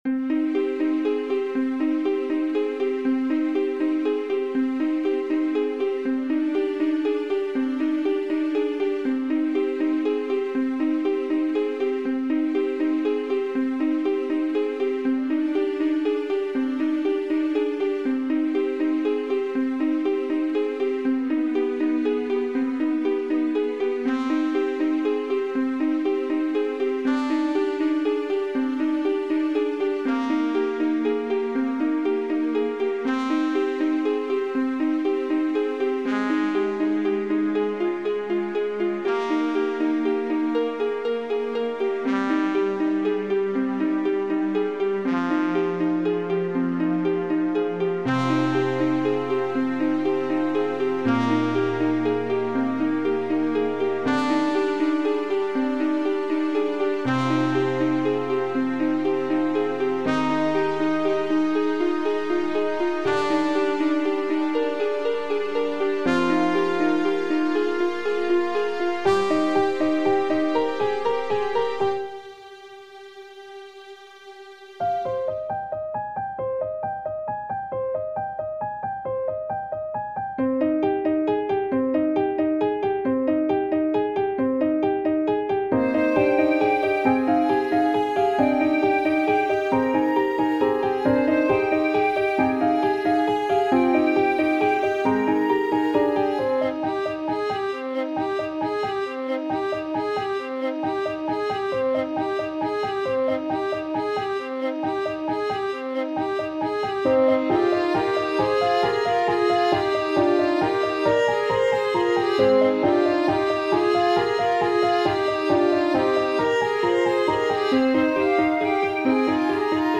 Quite pleased with the brass in this one.